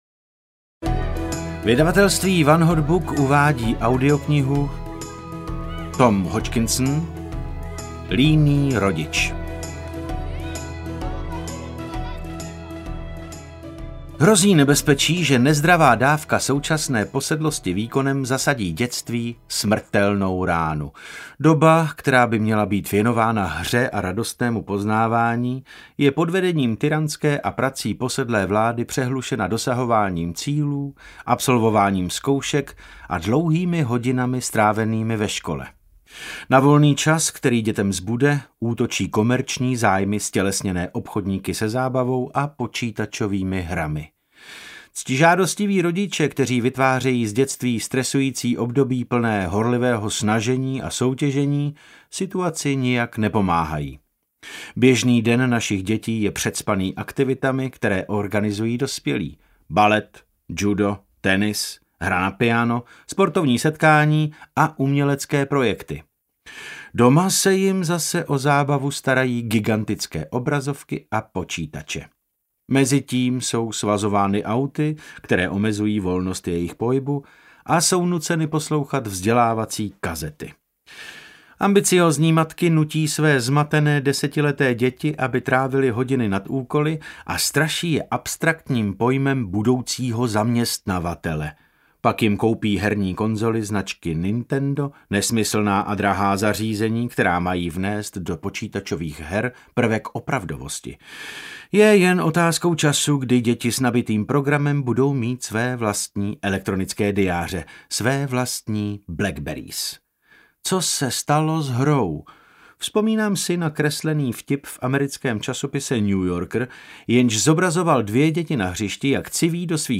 Líný rodič audiokniha
Ukázka z knihy
• InterpretDavid Novotný